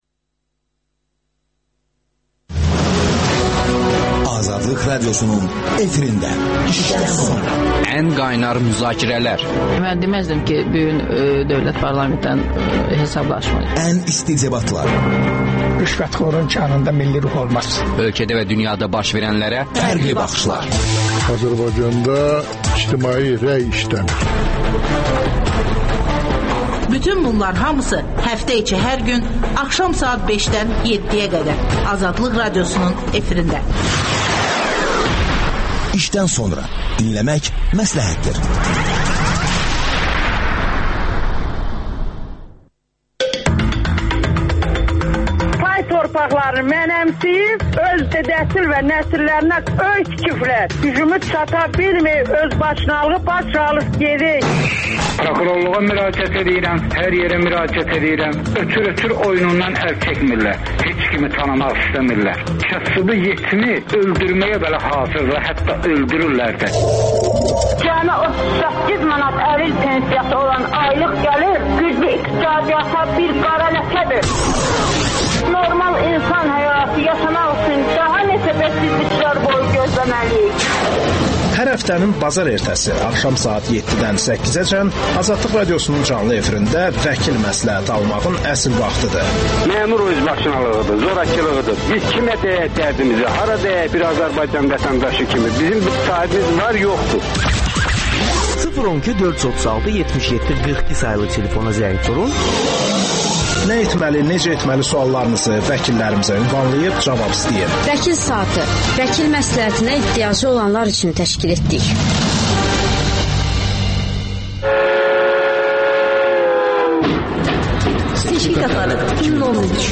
(Debat) Embed Kod yaddaşa götürüldü The URL has been copied to your clipboard No media source currently available 0:00 0:55:00 0:00